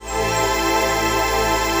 CHRDPAD080-LR.wav